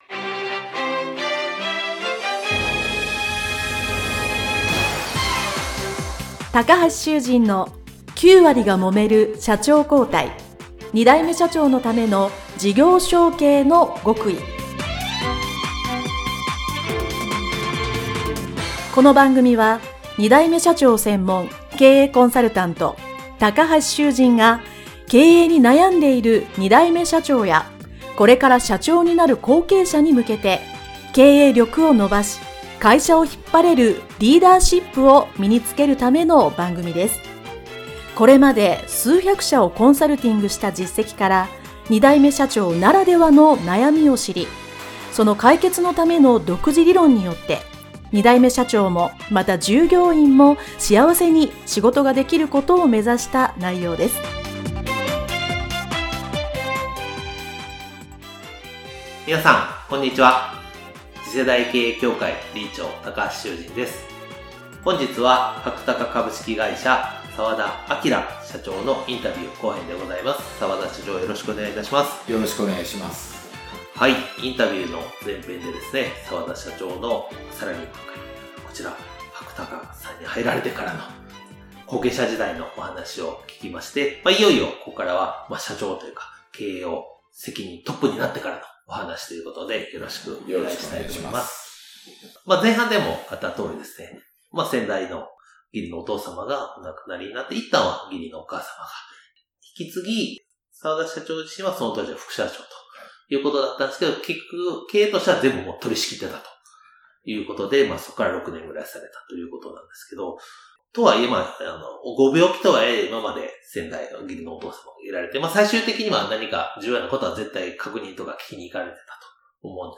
【インタビュー後編】